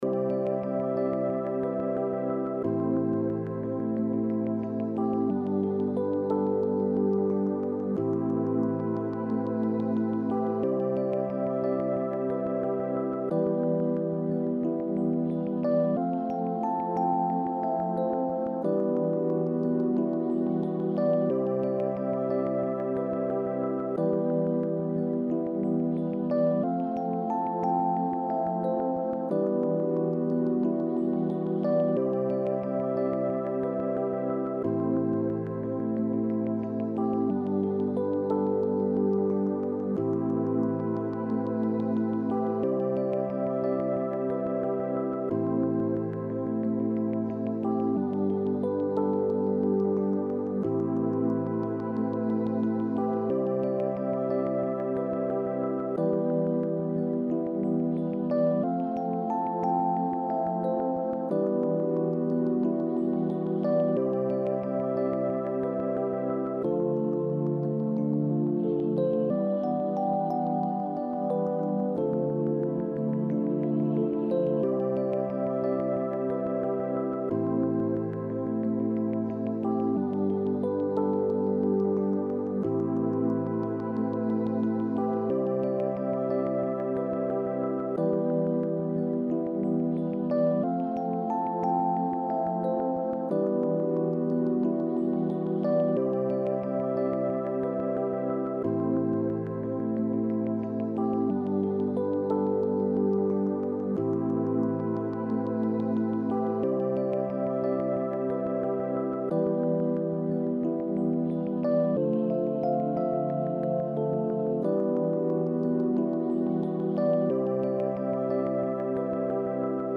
On peut même écouter un peu de musique douce pour accompagner la lecture.
douceur.mp3